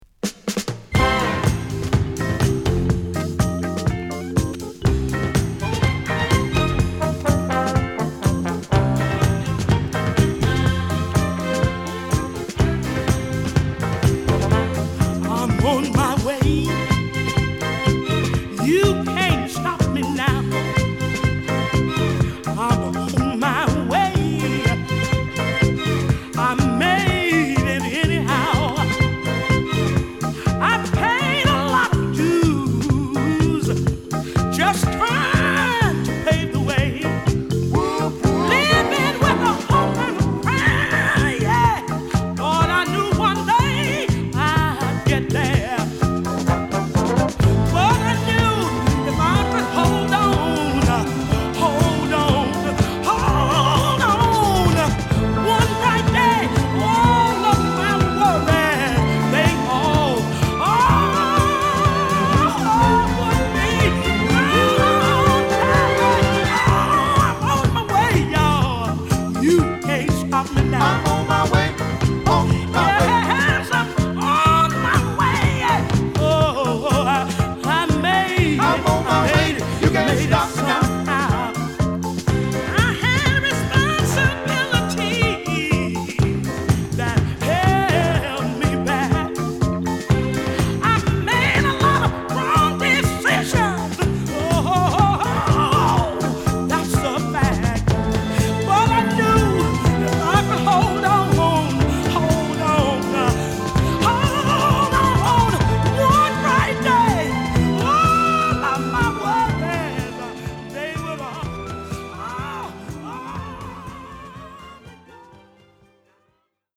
7inch
ディープでパワフルなヴォーカルが冴えるモダンダンサー／ディスコ！